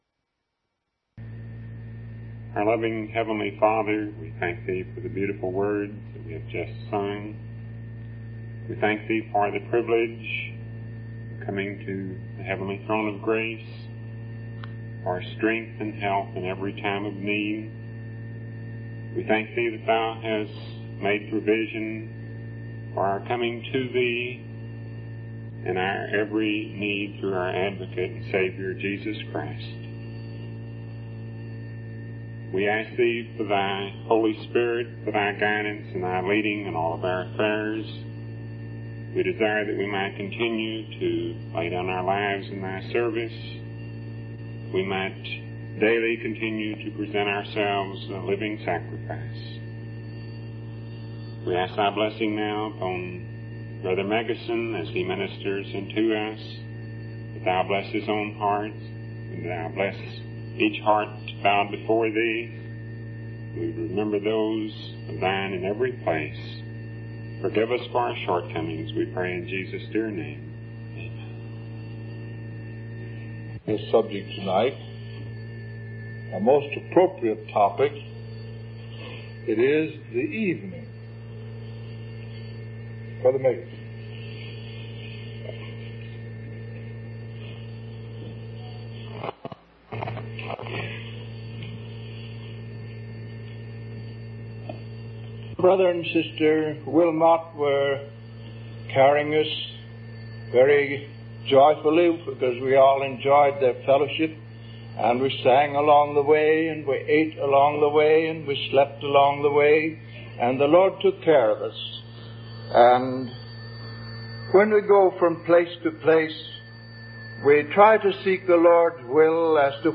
From Type: "Discourse"
Given at Asilomar Convention in 1962